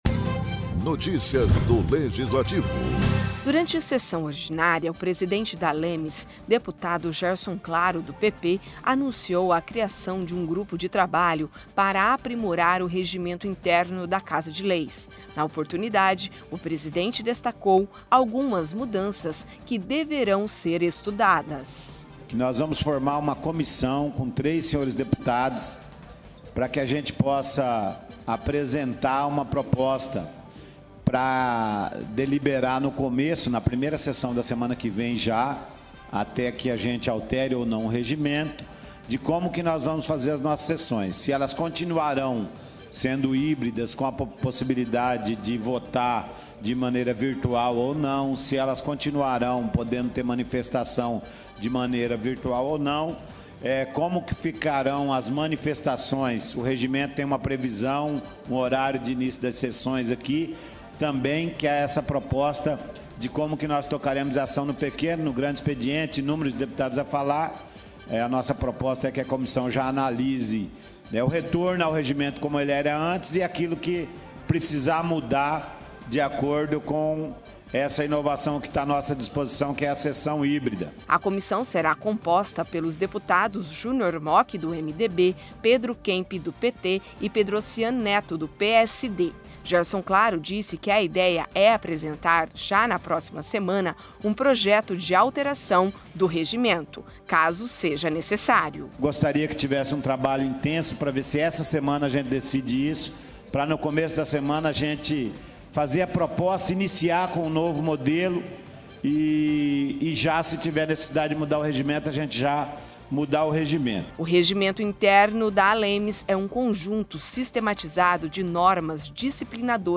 Durante sessão ordinária, o presidente da Assembleia Legislativa de Mato Grosso do Sul (ALEMS), deputado Gerson Claro (PP), anunciou a criação de um grupo de trabalho para aprimorar o Regimento Interno da Casa de Leis.